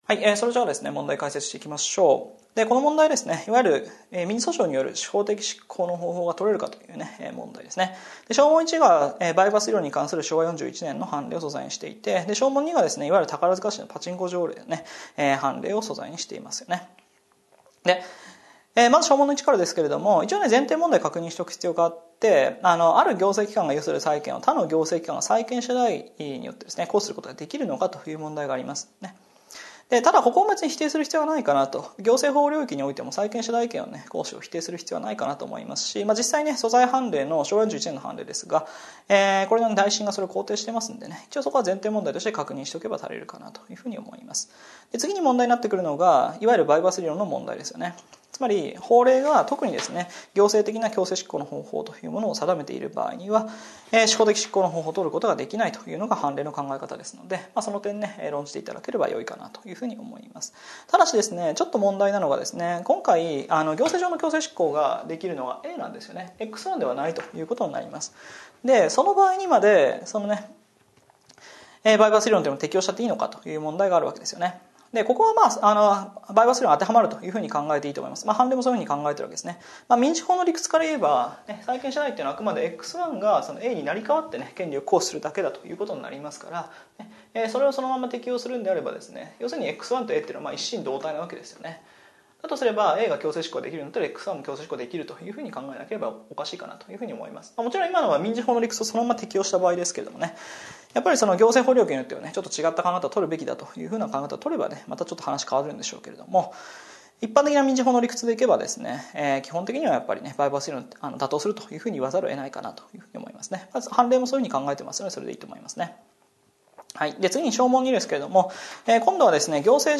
解説音声